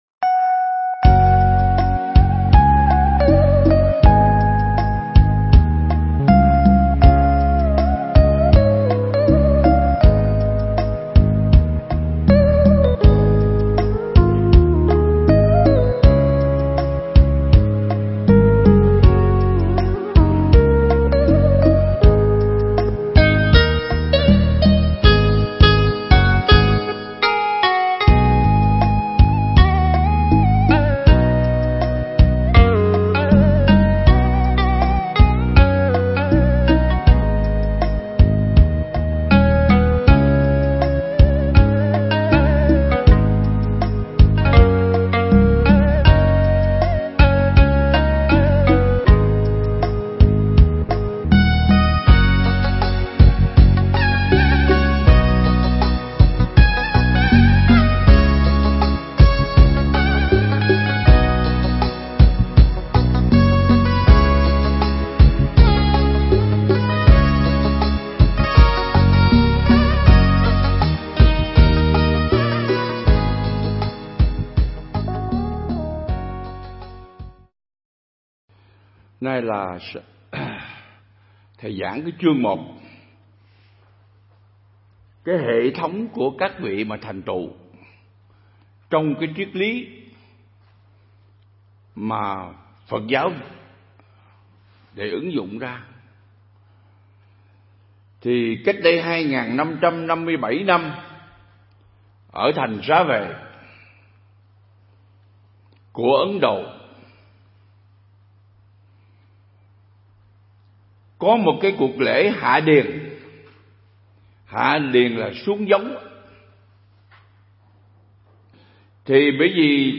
Mp3 Bài giảng Ứng Dụng Triết Lý Buddha Và Yoga Vào Cuộc Sống Phần 3
giảng tại Viện Nghiên Cứu Và Ứng Dụng Buddha Yoga Việt Nam (Hồ Tuyền Lâm, Đà Lạt)